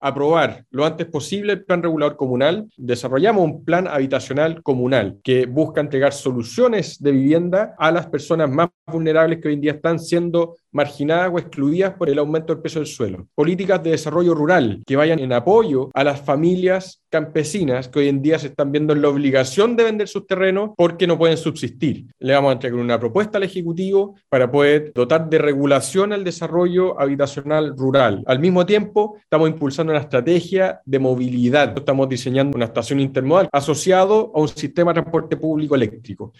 En entrevista con Radio Sago, el alcalde Tomás Garate conversó acerca de la migración interna del país, la que trajo como consecuencia un aumento explosivo de nuevos habitantes en la comuna de Puerto Varas.